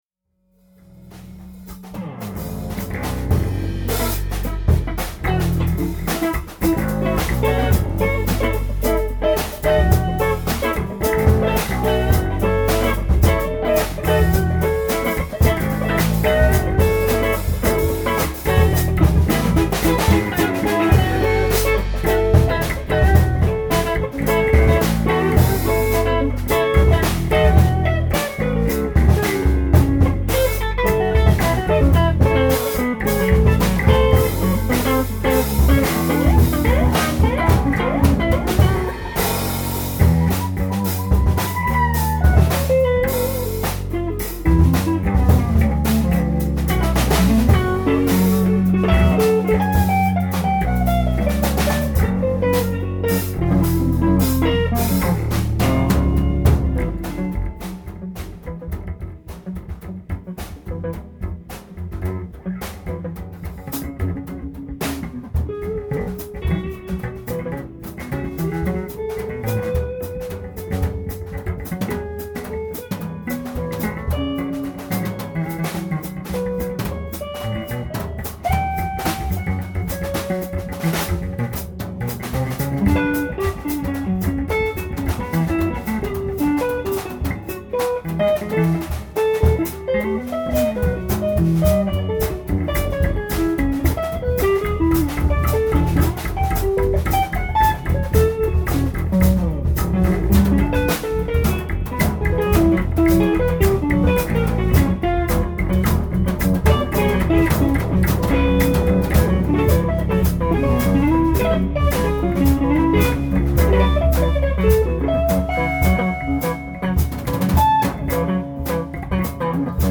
Warmup